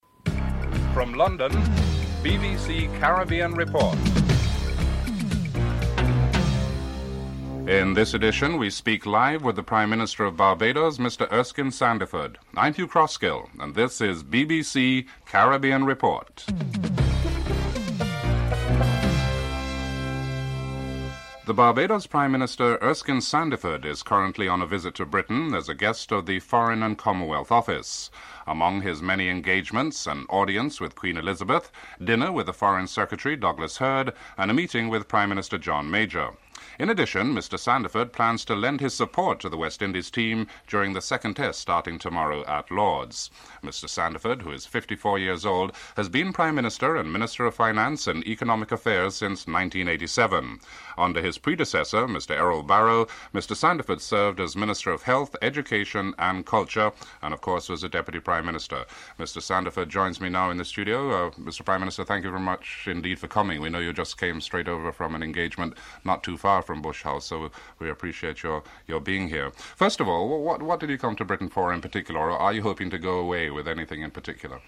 1. Headlines (00:00-00:21)